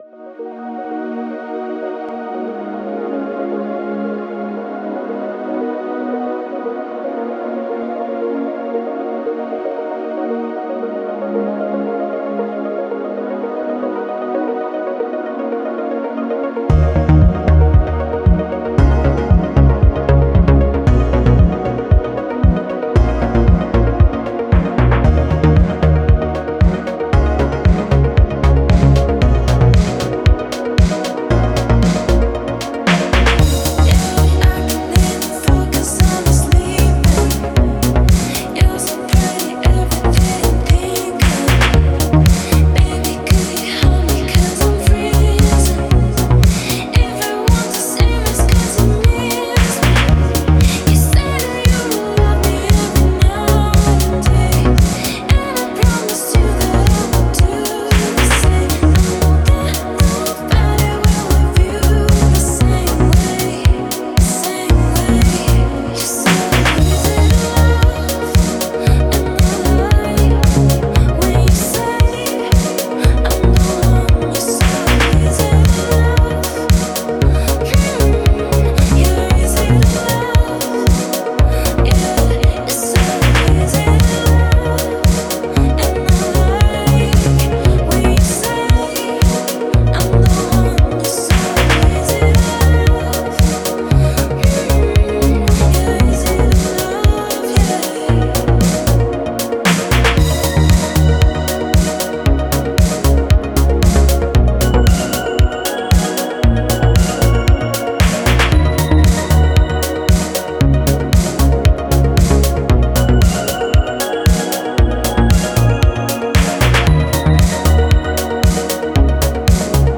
завораживающая электронная композиция